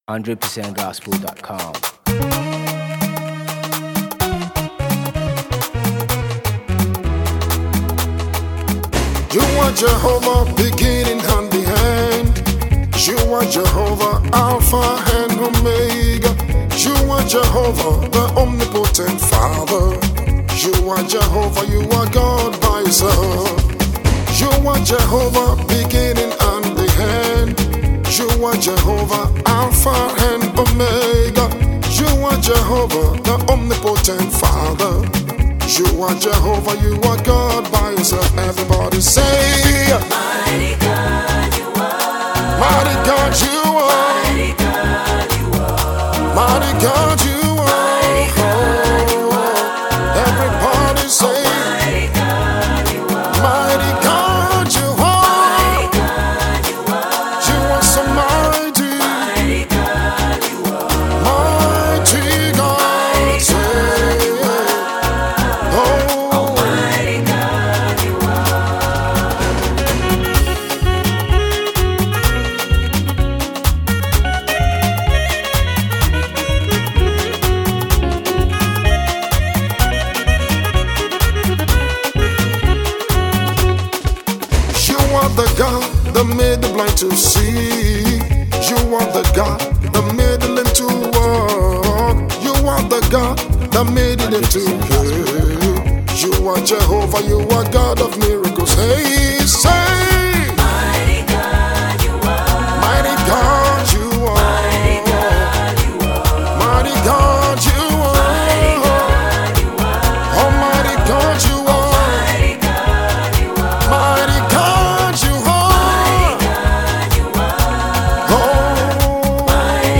high praise song